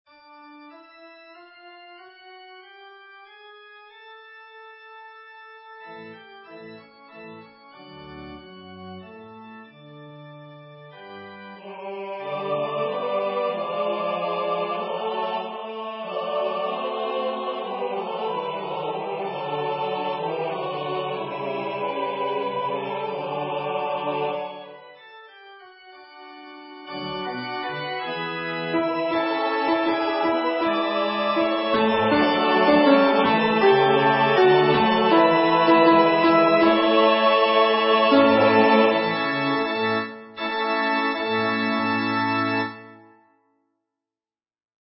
Practice files: Soprano:     Alto:
Edition notes: This edition was abbreviated to take about the same time as a Gregorian Chant Mass. Also, it was transposed for typical choir ranges.
SchubertMassGAbrvBeneAltoP.mp3